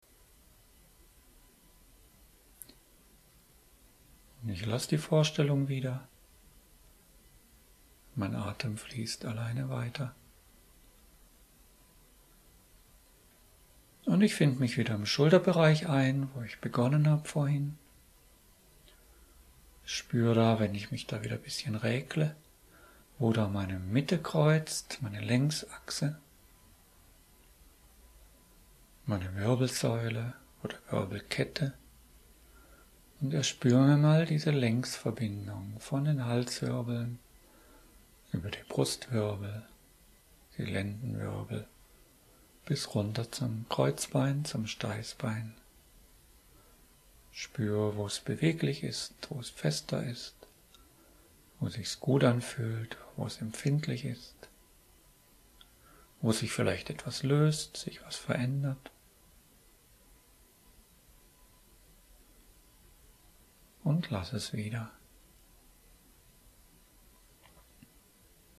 Dabei können Sie sich von meiner Stimme begleiten lassen.
Dort hören Sie sich die Anleitung an und folgen ihr auf die Weise, die Ihnen im Moment entspricht.